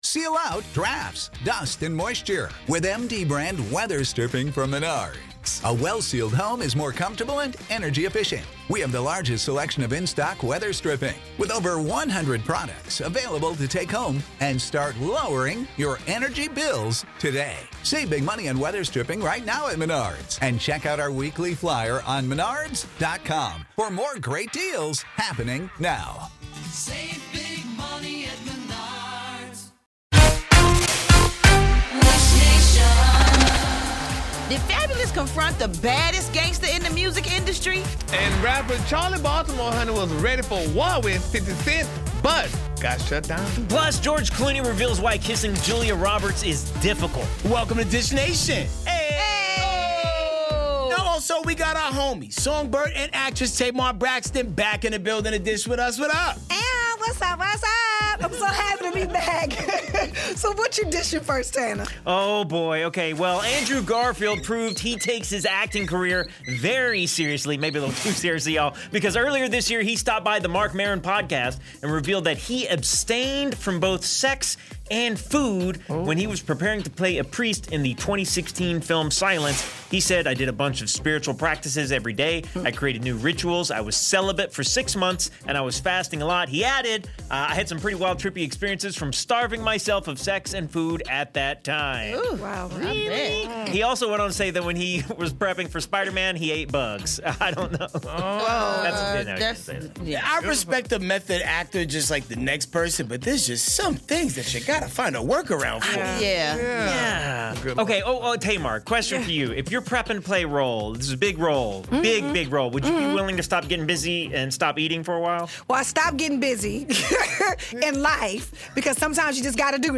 Tamar Braxton co-hosts with us, so tune in to today's Dish Nation for some celebri-tea!